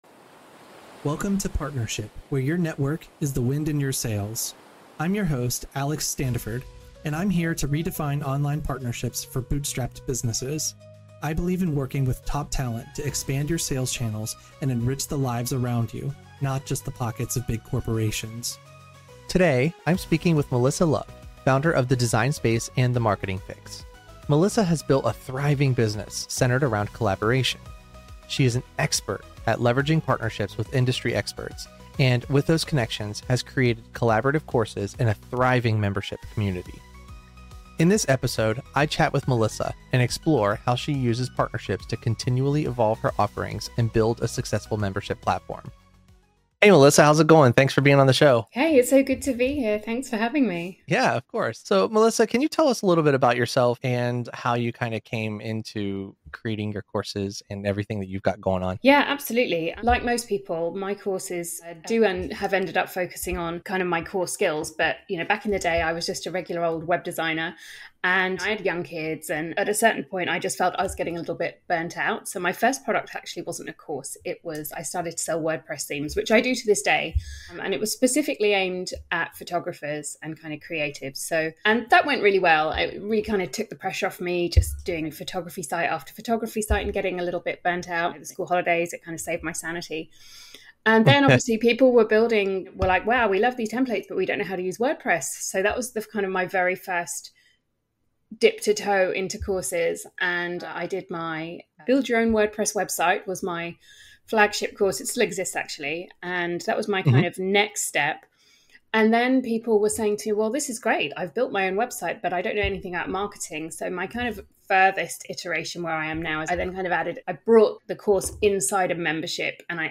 In this conversation, she reveals how partnerships have been central to evolving her offerings and creating a dynamic platform that serves her members well.